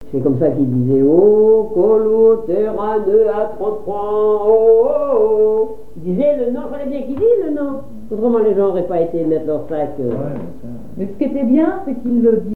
Cris du ferrailleur
Conversation autour des chansons et interprétation
Pièce musicale inédite